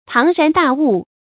成語注音 ㄆㄤˊ ㄖㄢˊ ㄉㄚˋ ㄨˋ
成語拼音 páng rán dà wù
龐然大物發音